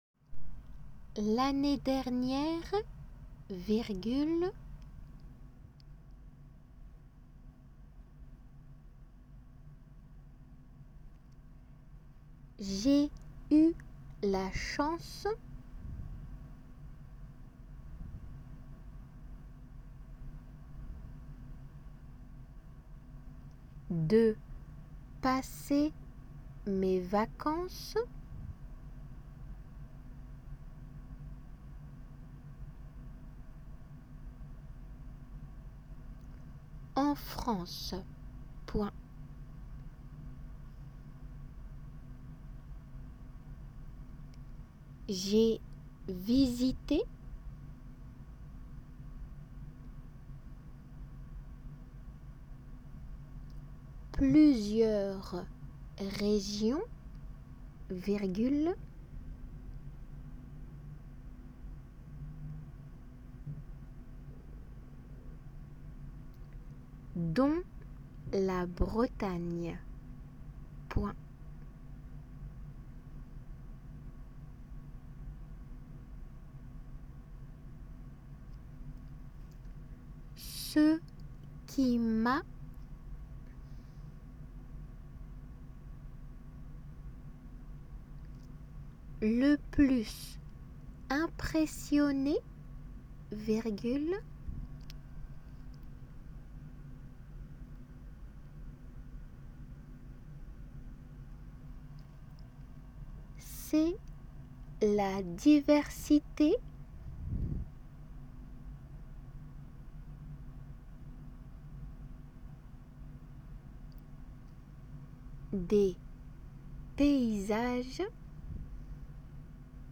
仏検　2級　デイクテー音声　秋１
デイクテの速さで一回のみ読まれます。